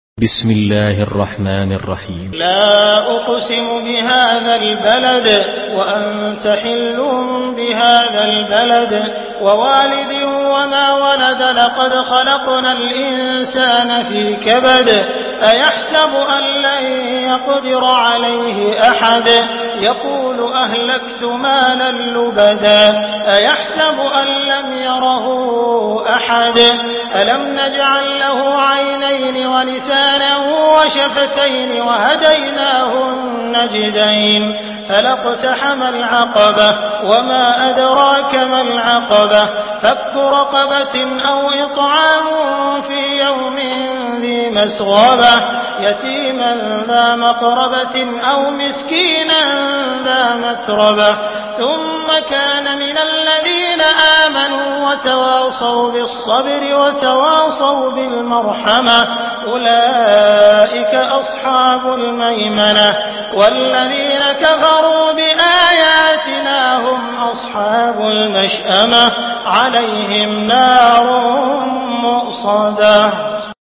Surah Al-Balad MP3 by Abdul Rahman Al Sudais in Hafs An Asim narration.
Murattal Hafs An Asim